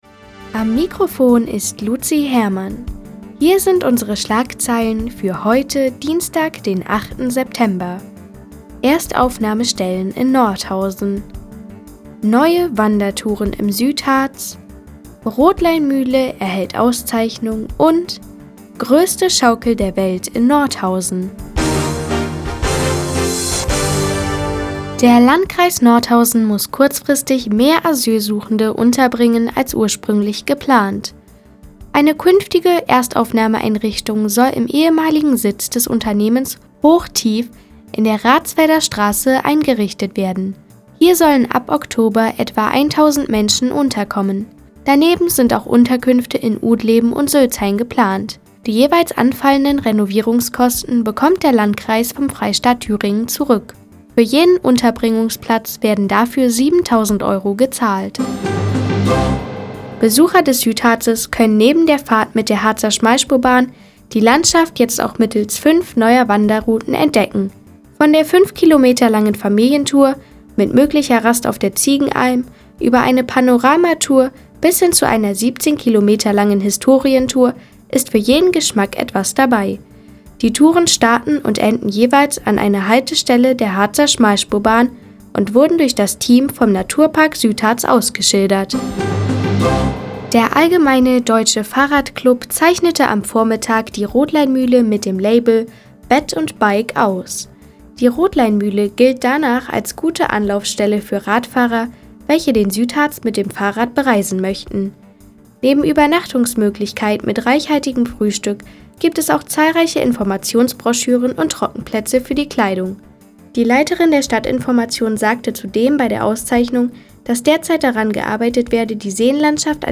Di, 17:00 Uhr 08.09.2015 Neues vom Offenen Kanal Nordhausen „Der Tag auf die Ohren“ Seit Jahren kooperieren die Nordthüringer Online-Zeitungen, und der Offene Kanal Nordhausen. Die tägliche Nachrichtensendung des OKN ist jetzt hier zu hören.